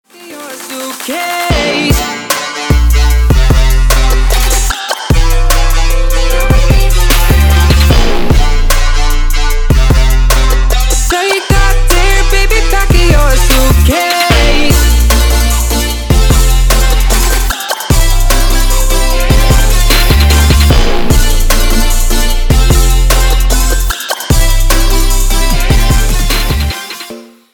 • Качество: 320, Stereo
Electronic
Trap
Bass
vocal